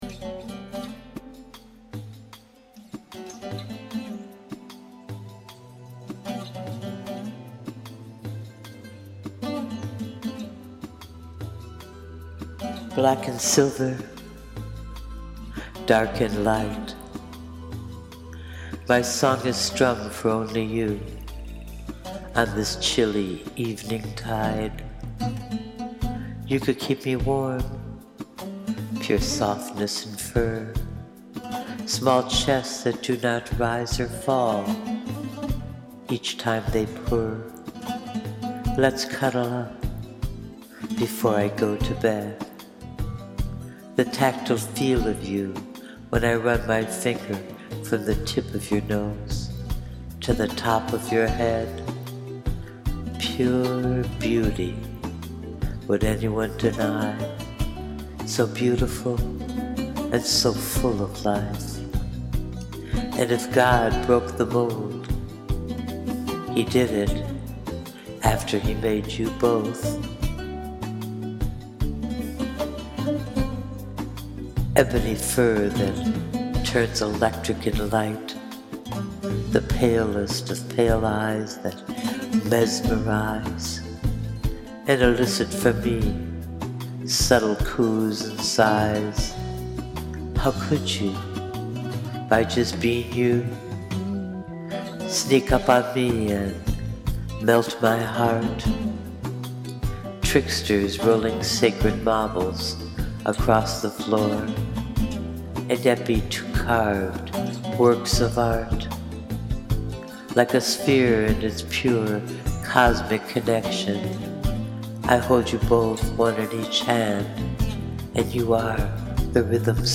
I wrote and recorded this poem, especially for her blog.